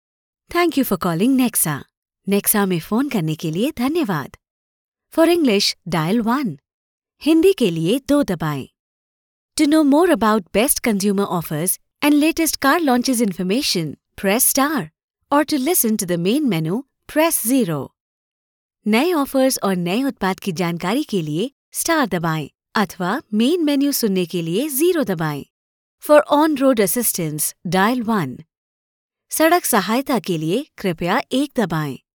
Joven, Natural, Versátil, Amable, Cálida
Telefonía